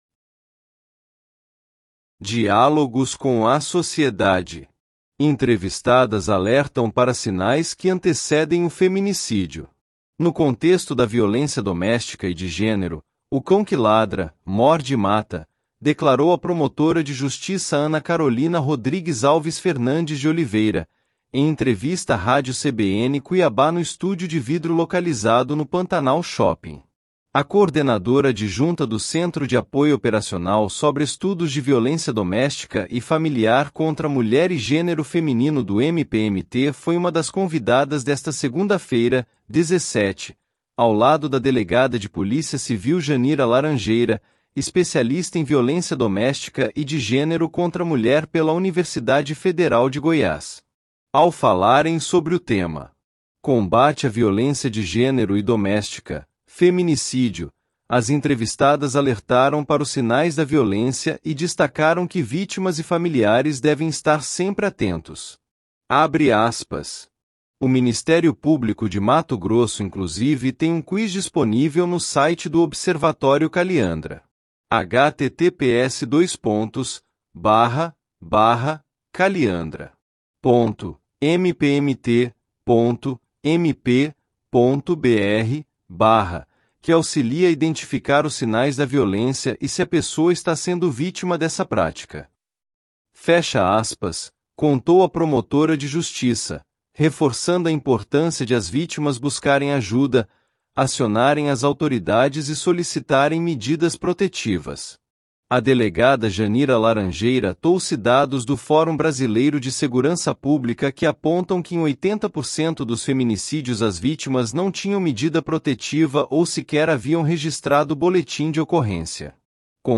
Entrevistadas alertam para sinais que antecedem o feminicídio ‐ .mp3